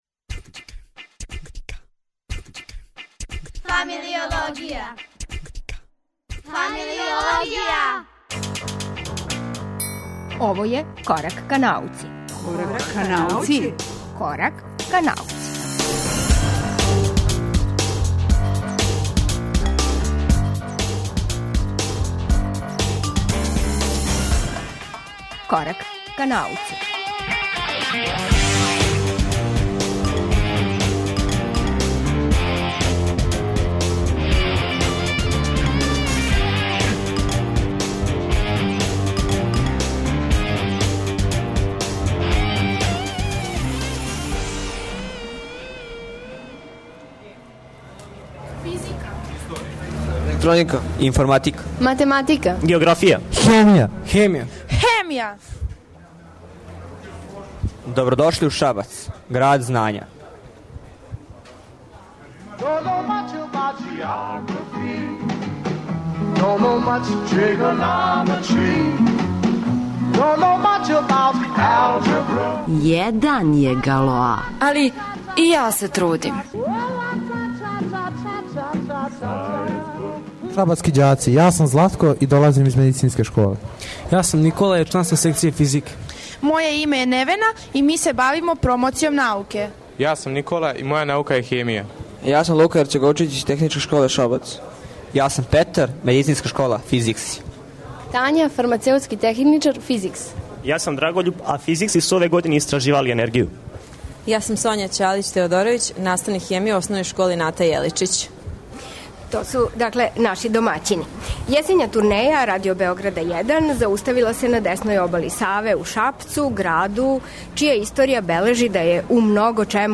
У 'Кораку ка науци' и даље постављамо питање: има ли науке у Србији? Овога пута, емисију емитујемо из Шапца.